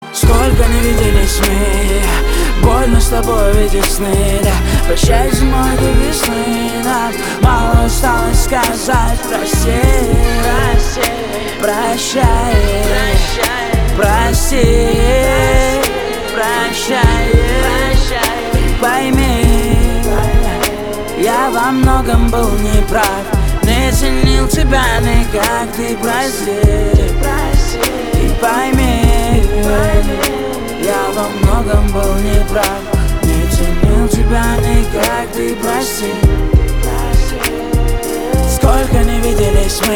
лирика
Хип-хоп
Саксофон
Bass